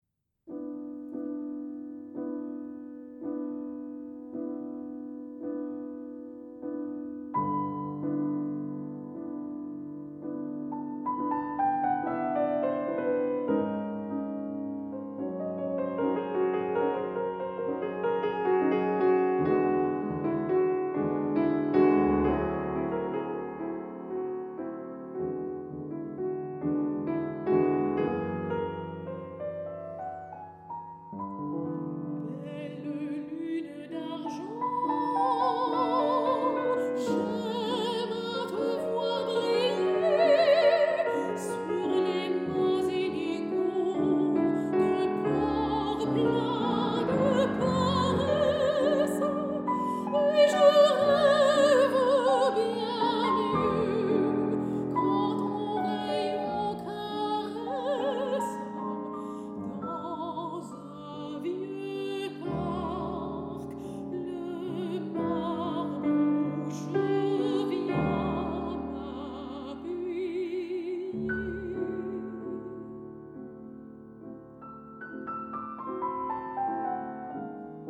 藝術歌曲